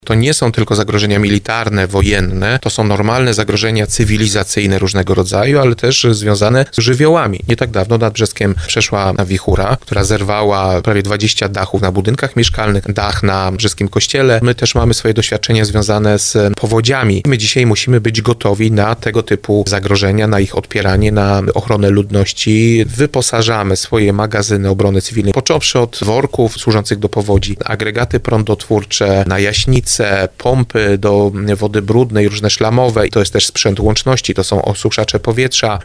Jak podkreśla burmistrz Brzeska Tomasz Latocha, chodzi o sprzęt, który będzie przydatny w przypadku lokalnych zagrożeń.